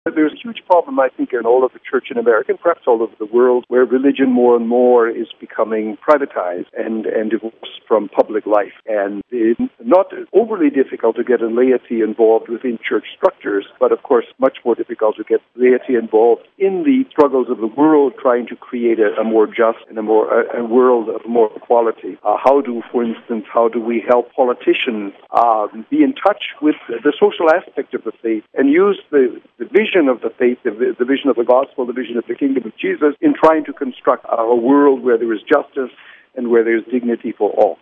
James Weisgerber, the Archbishop of Winnipeg, told us more about the meeting...